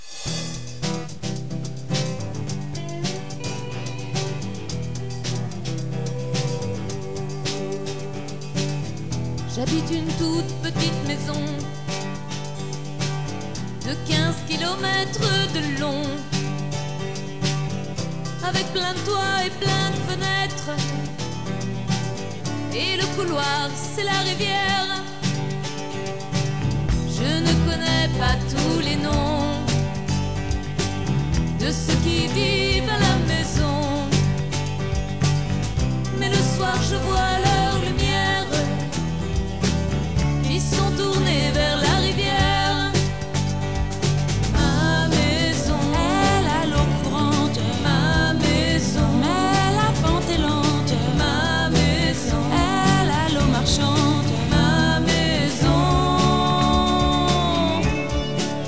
Musique Ethnique de la Basse Vallée de l'Ourcq